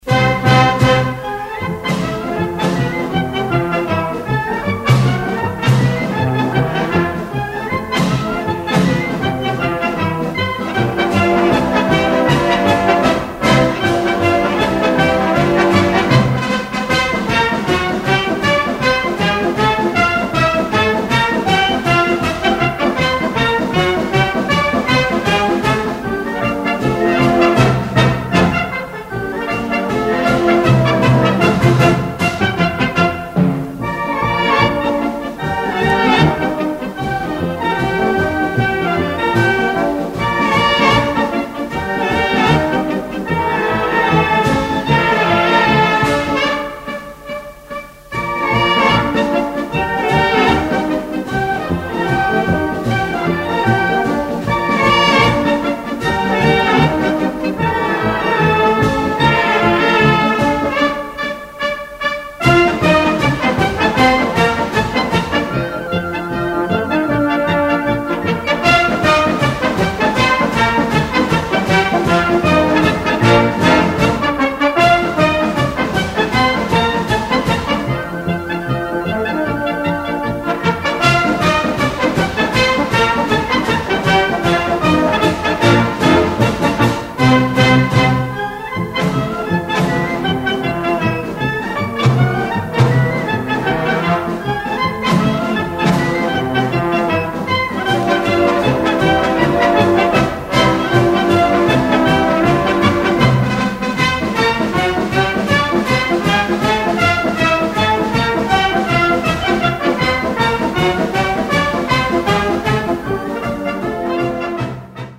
Gattung: Polka-Schnell
Besetzung: Blasorchester